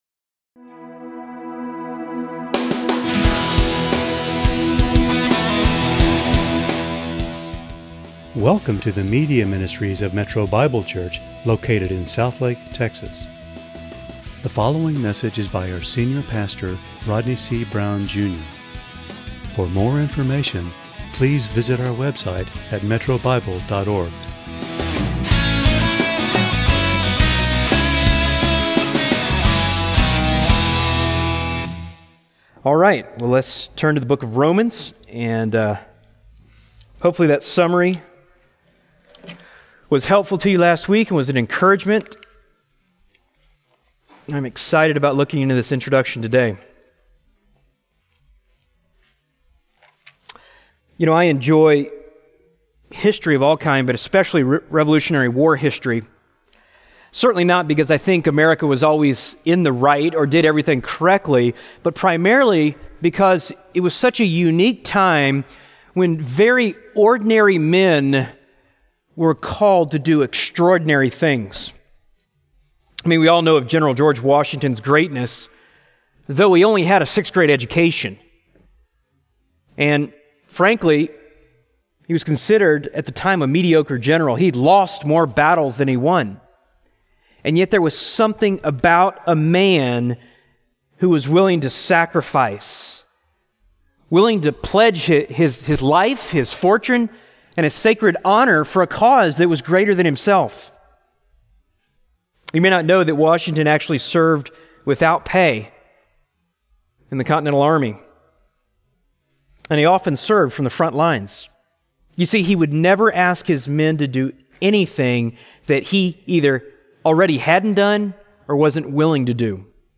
× Home About sermons Give Menu All Messages All Sermons By Book By Type By Series By Year By Book Don’t Waste Your Life Live the life He gave us and proclaim the message that saved us.